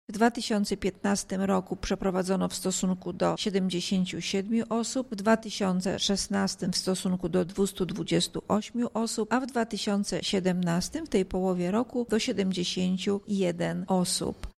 Ile dokładnie postępowań egzekucyjnych wszczęto w naszym województwie, mówi Irmina Nikiel, Lubelski Wojewódzki Inspektor Sanitarny